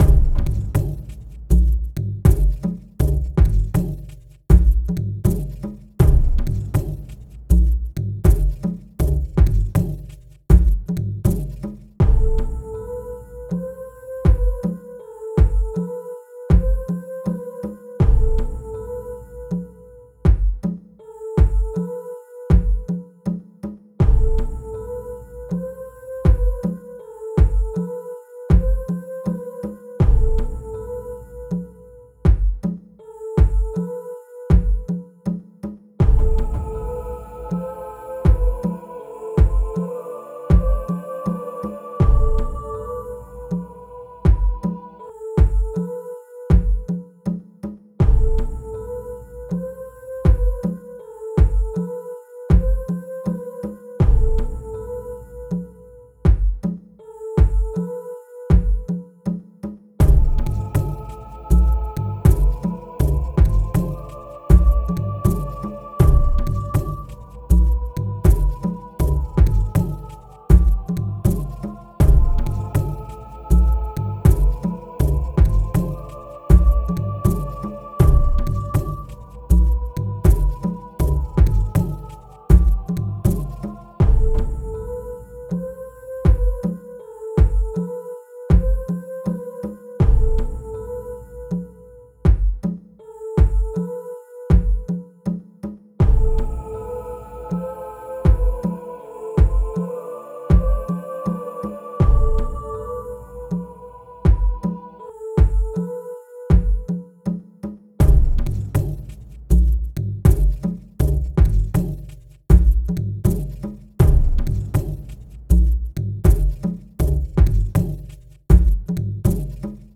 2021, performance et installation sonore, pièce durative
Je pensais que ça irait mieux... est une reconstitution générale d'événements, une collection de souvenirs et une redéfinition de la narration à travers des sons descriptifs performatifs et familiers dans mon foyer indigène.